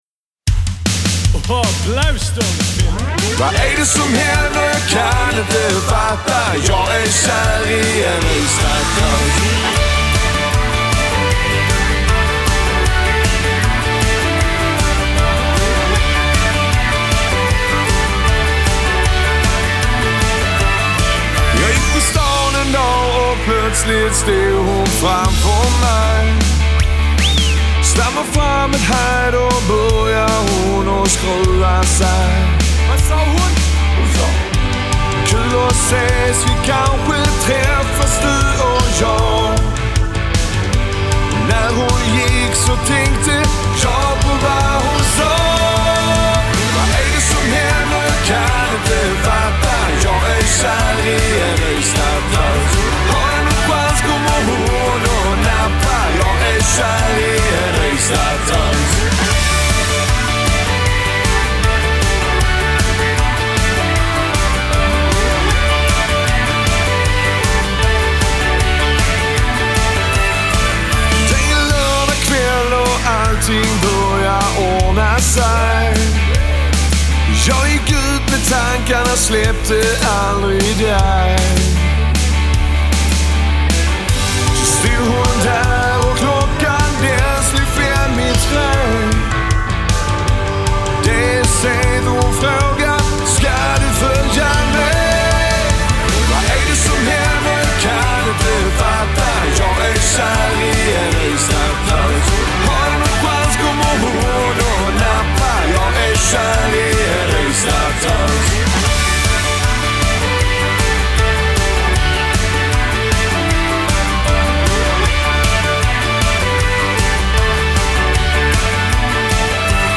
Väletablerat coverband som utgår från Skåne.